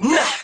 Naaaaaa.wav